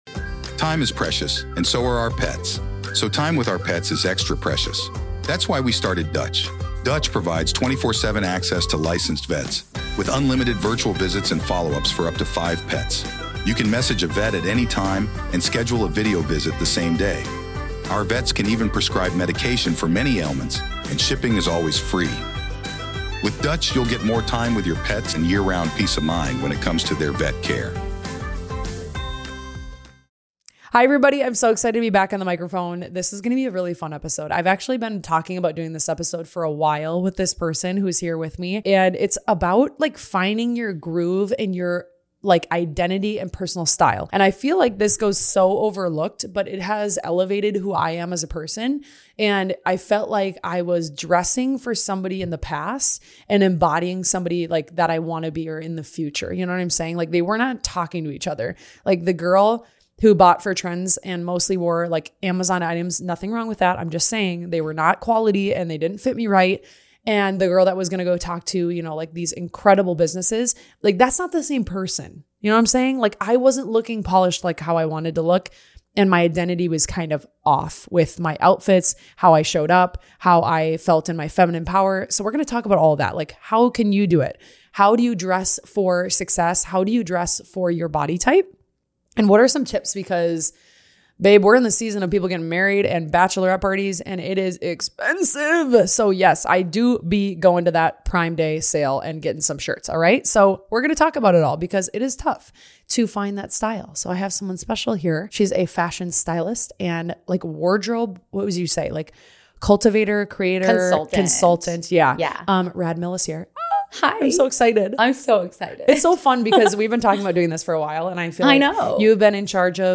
This is a conversation about building confidence through what you wear and finding a style that actually feels like you.